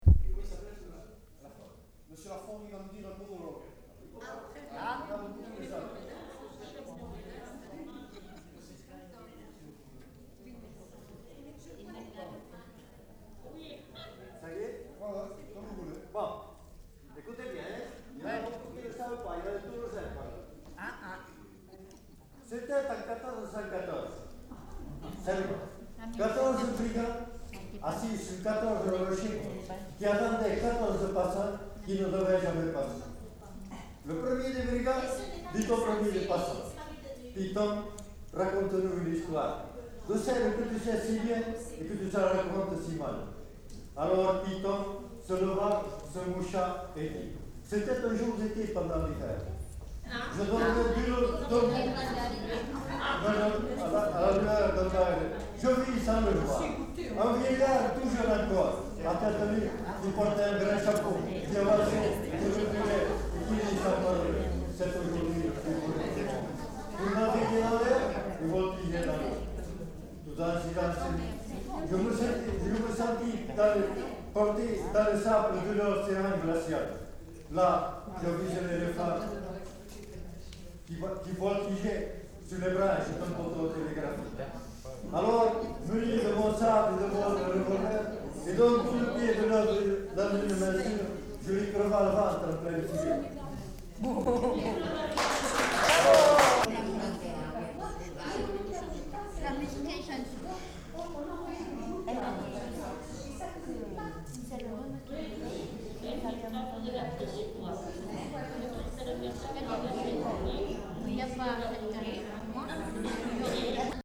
Aire culturelle : Lauragais
Genre : conte-légende-récit
Effectif : 1
Type de voix : voix d'homme
Production du son : récité
Classification : monologue